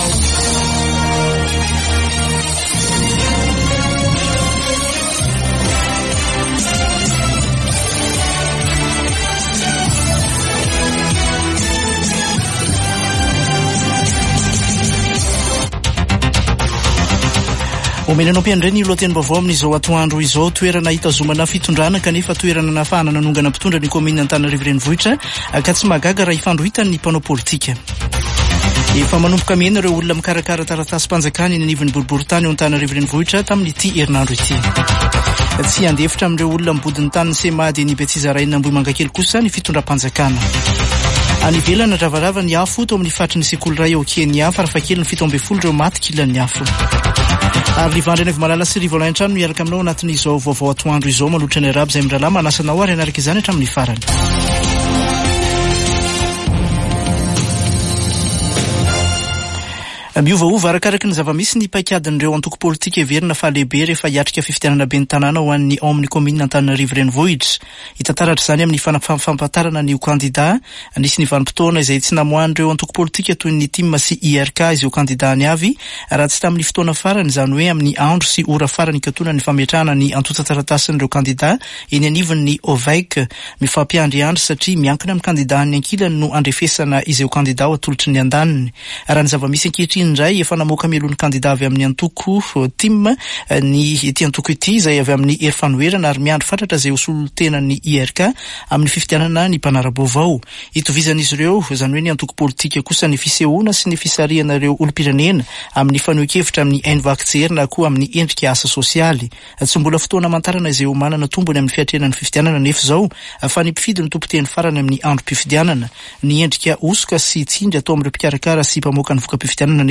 [Vaovao antoandro] Zoma 6 septambra 2024